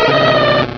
Cri de Teddiursa dans Pokémon Rubis et Saphir.
Cri_0216_RS.ogg